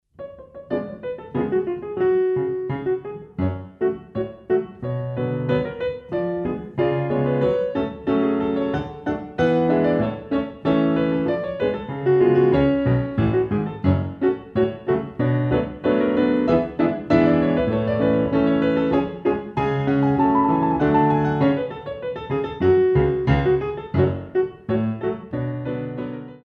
Compositions for Ballet Class
Battements frappé